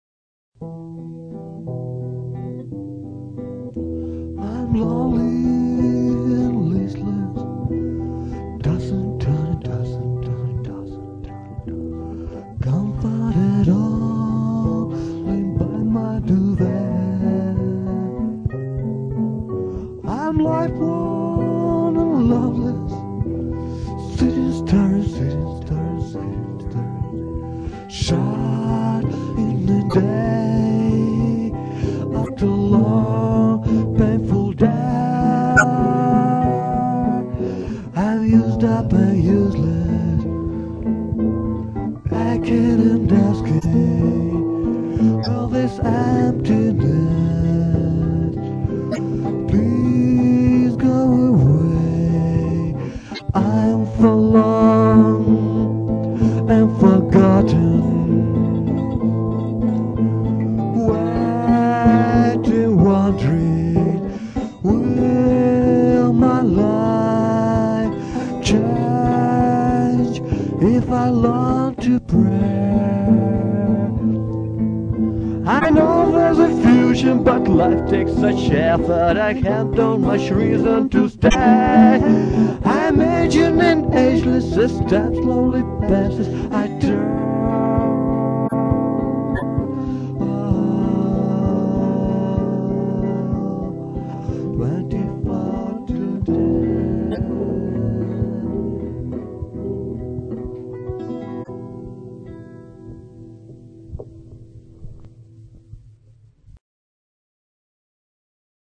The beat sound on the background (in compound meter) immitates my heart beat.